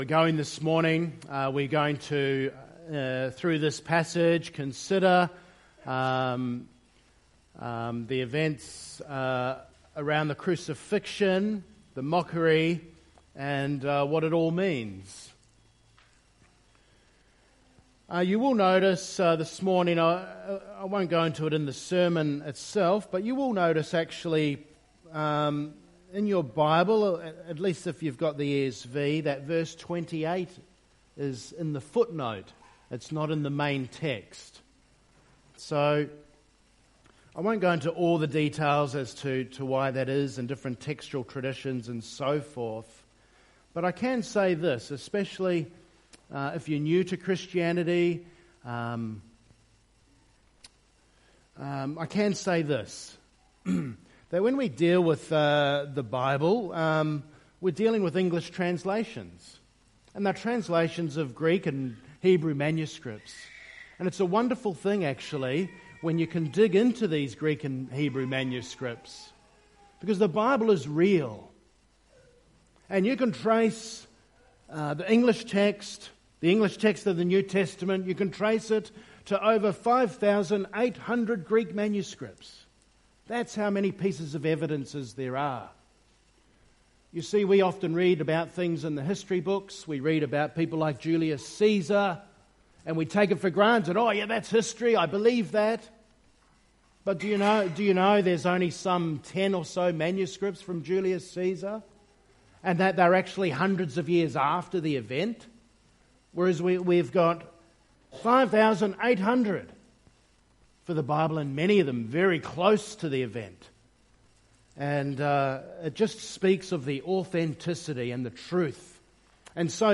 Mocked on our behalf (Good Friday Service)